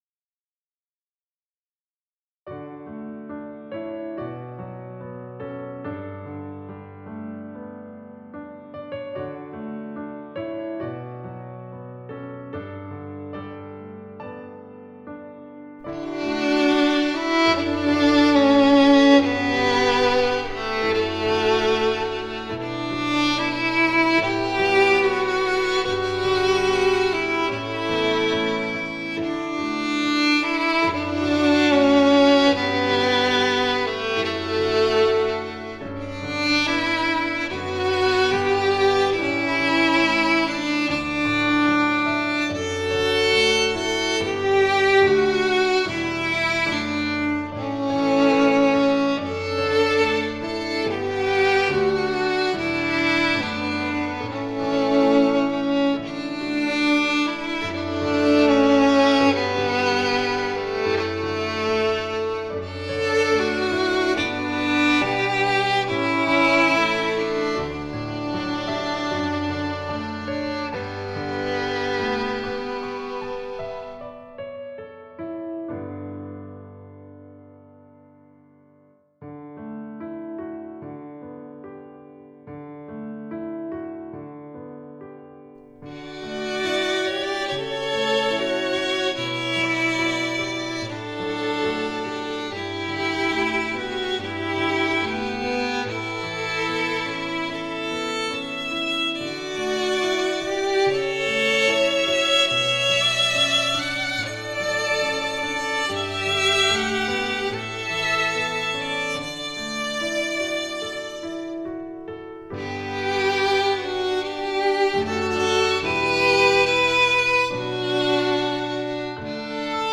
Instrumentation: 2 Violins with Piano accompaniment